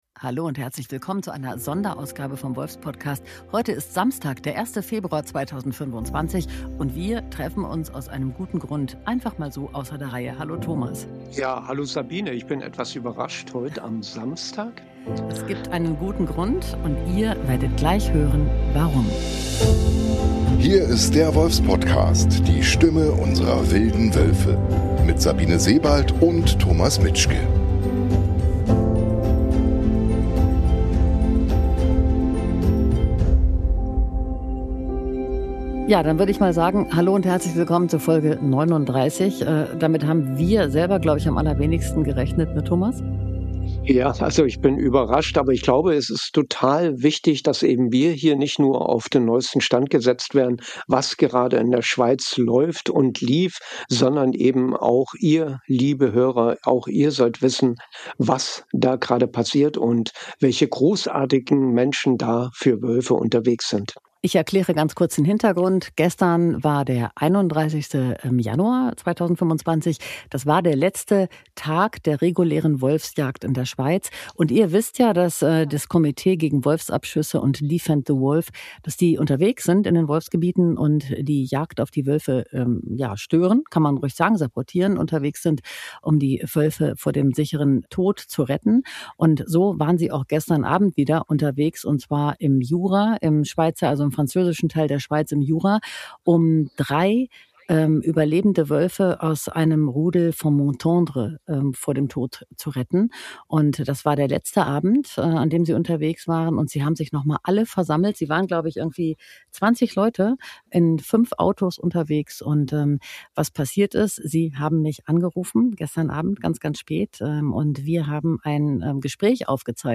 Herausgekommen ist eine unglaubliche Momentaufnahme mit Gänsehaut-Garantie.